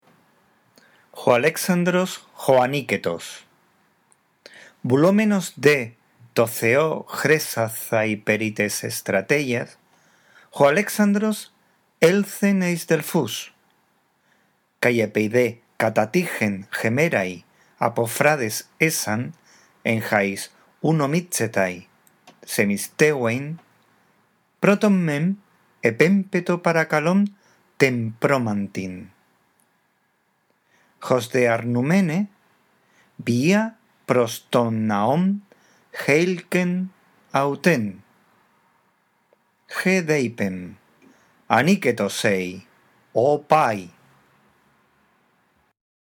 La audición de este archivo te ayudará en la práctica de la lectura de la lengua griega